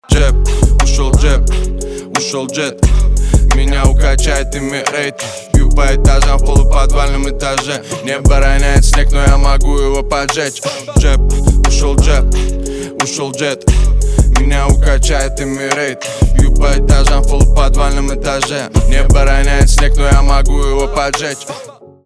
русский рэп , битовые , басы
пианино